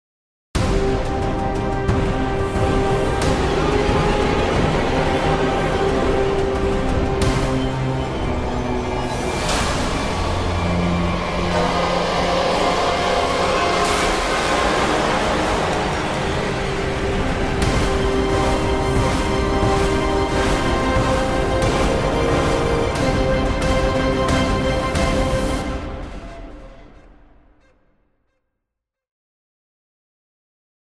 Epic horror track for trailers.